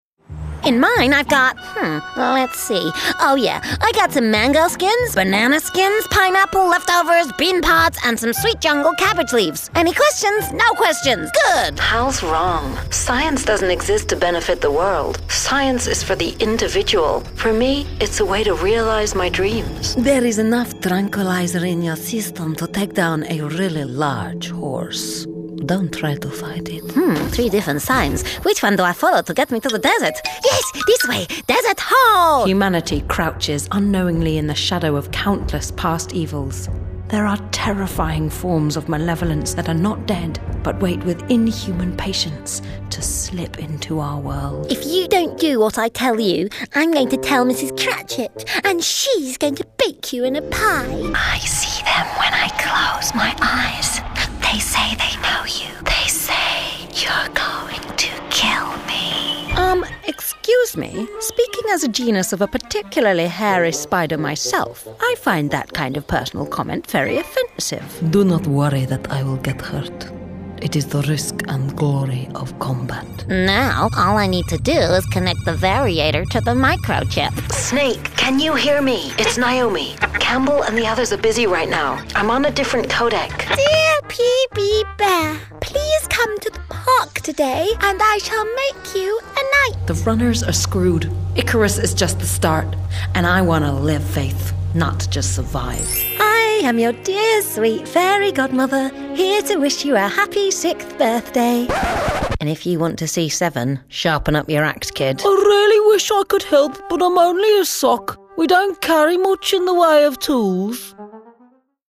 • Native Accent: London, Neutral, RP
• Home Studio